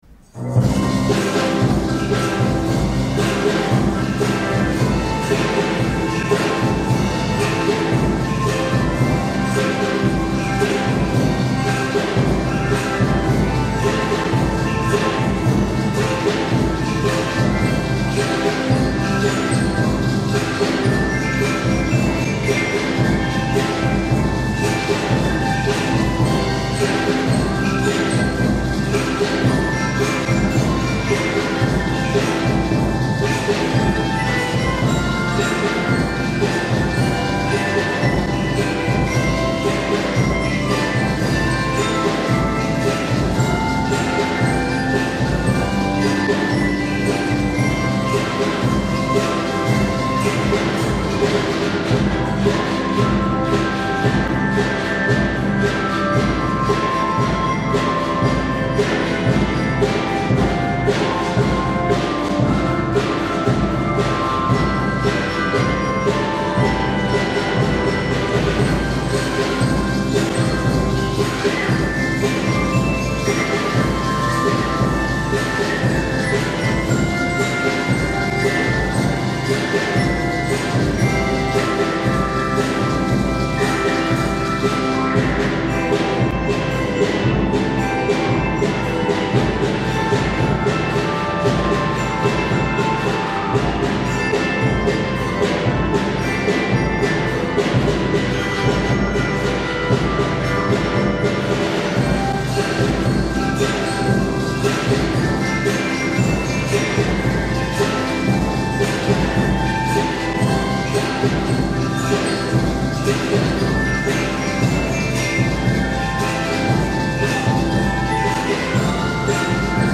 2年生は、ギロやカホン、コンガなど初めて見た打楽器にもチャレンジしました。
２年生の元気でノリノリな演奏と４年生のみんなを包み込むような優しい演奏ができました。